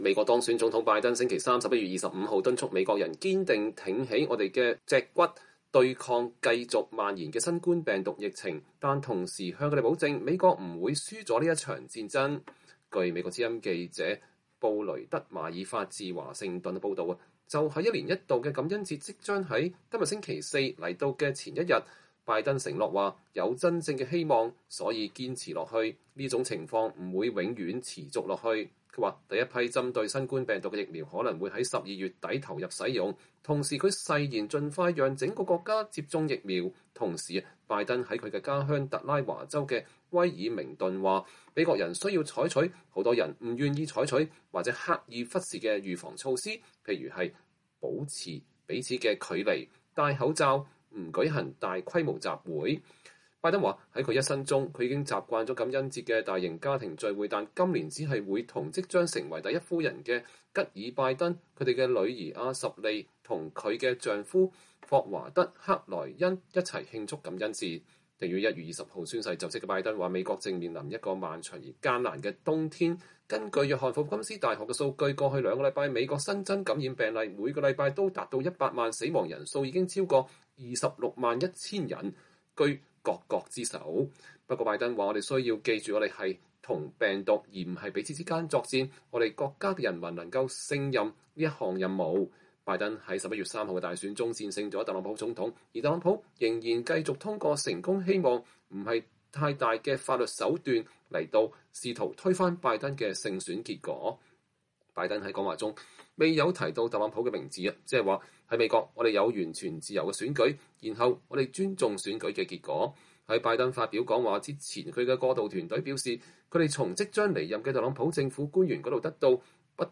美國當選總統拜登在威爾明頓的女王劇院發表講話。（2020年11月25日）